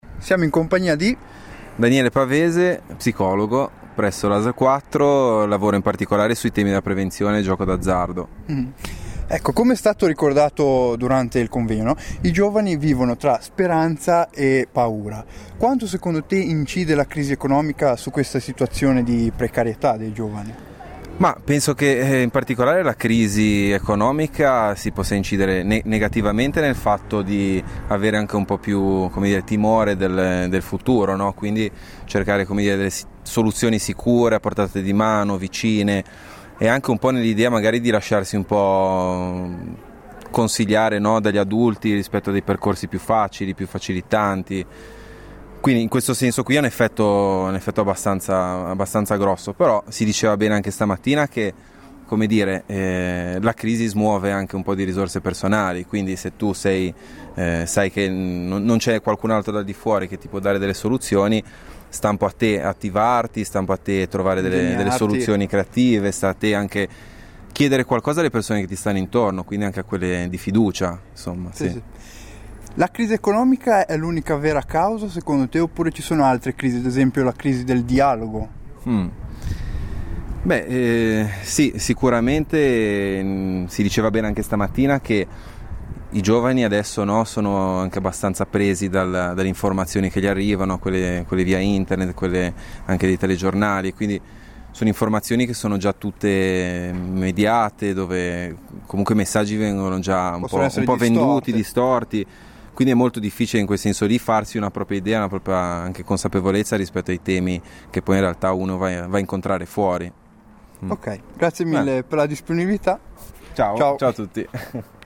Intervista a Passante
Intervista di un passante durante il convegno degli Infogiovani del Tigullio